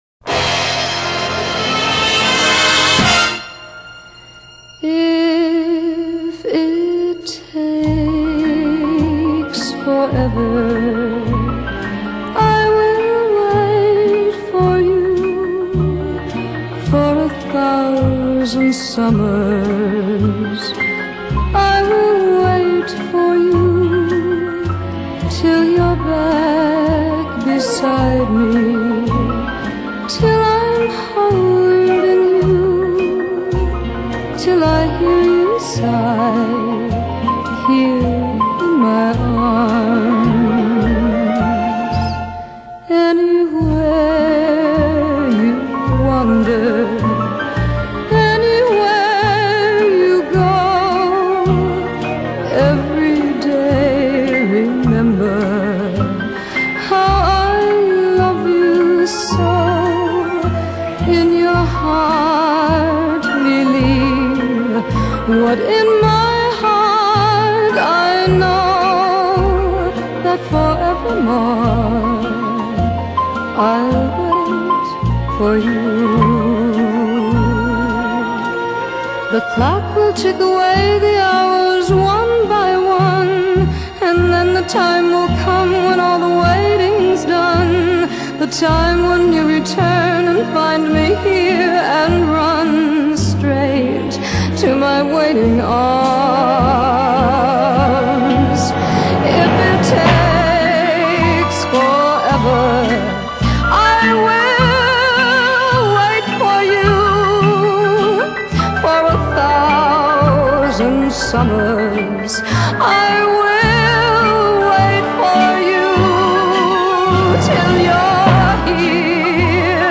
Genre: Dance / Soul / Easy Listening ...